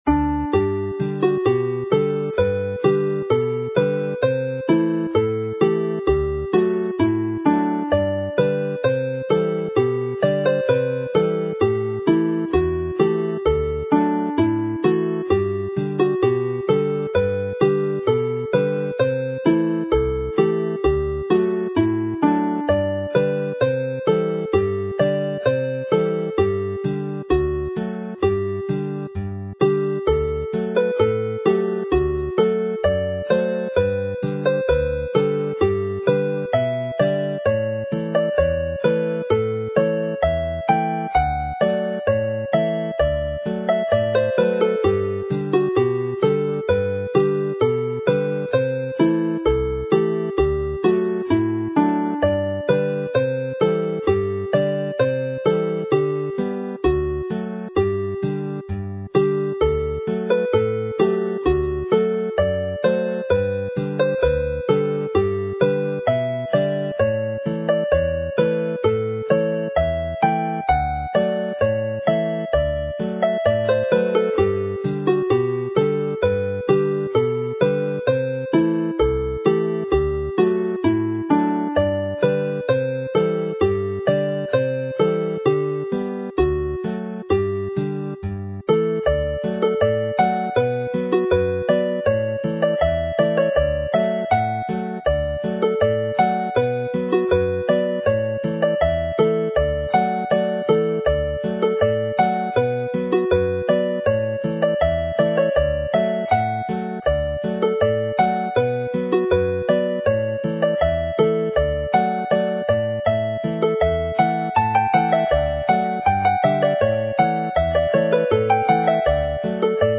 Alawon Cymreig - Set Agoriad y Blodau set - Welsh folk tunes
Set Agoriad y Blodau, i gyd yn G